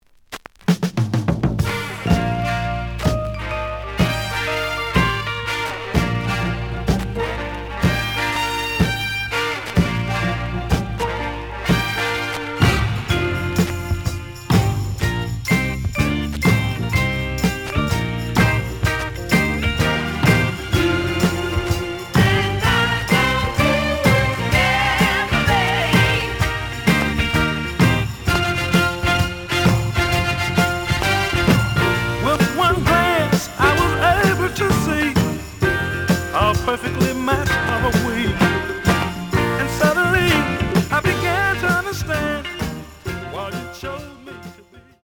The audio sample is recorded from the actual item.
●Genre: Soul, 70's Soul
Noticeable periodic noise on beginning of B side.